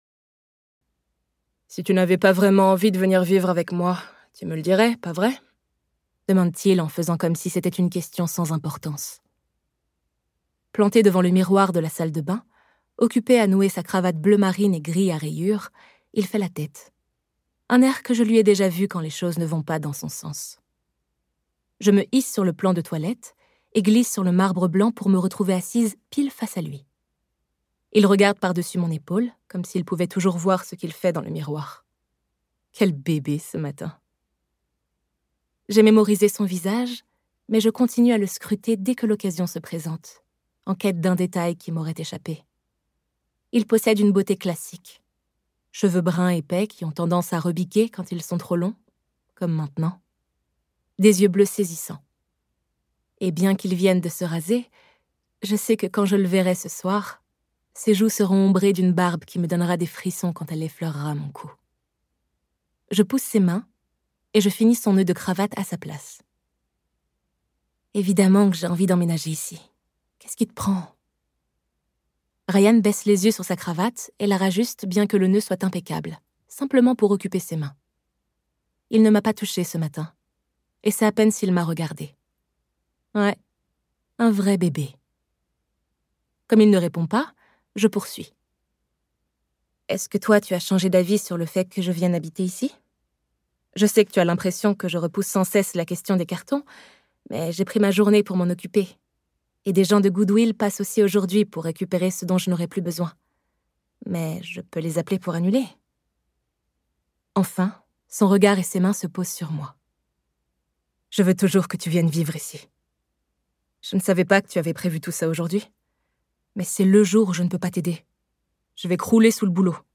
Laissez-vous envoûter par ce thriller plein de faux-semblants, narré par une voix grave et nuancée